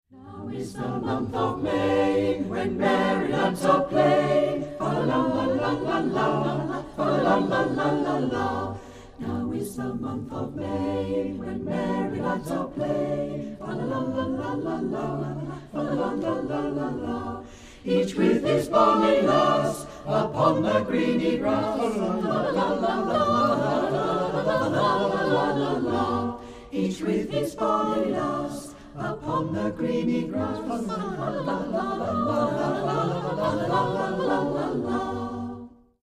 Musyck Anon, a cappella choir in Evesham, Worcestershire and Gloucestershire